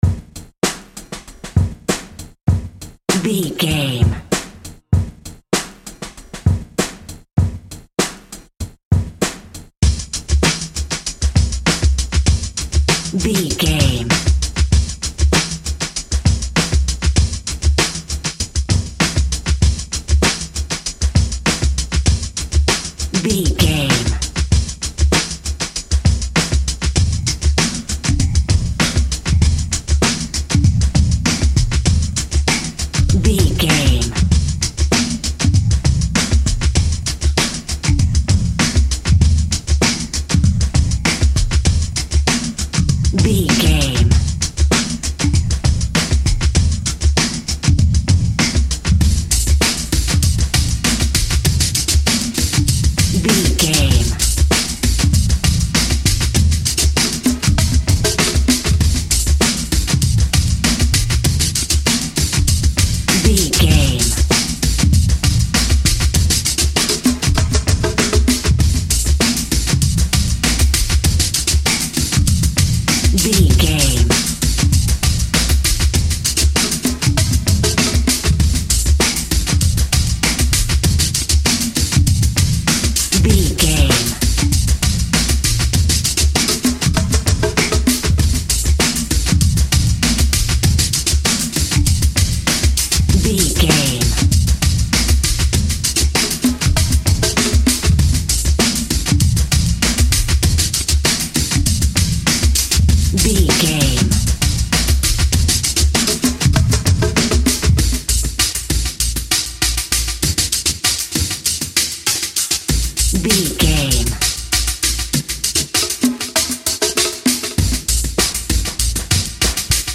Atonal
D